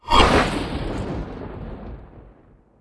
archmage_attack23.wav